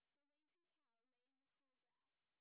sp17_white_snr20.wav